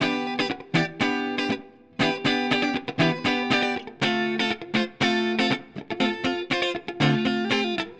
29 Guitar PT1.wav